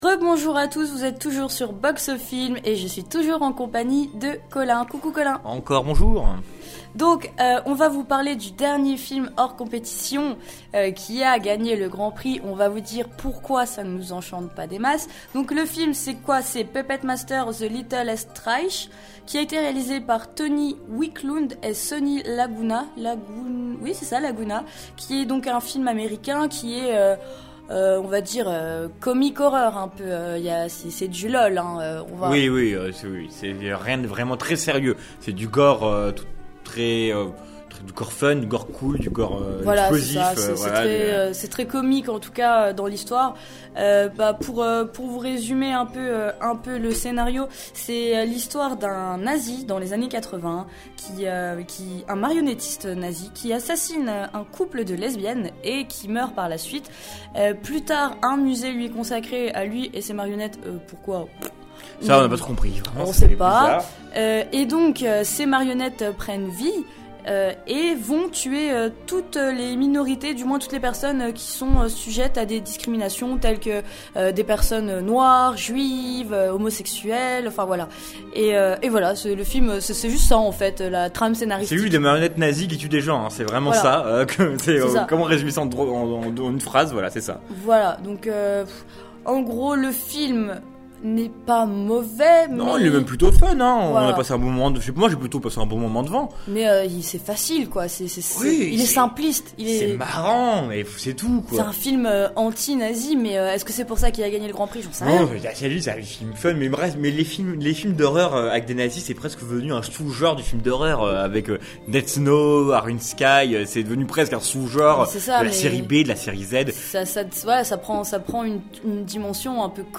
Vous pouvez entendre aux débuts de certaines critiques l’avis des spectateurs récupérés à chaud à la fin de la séance.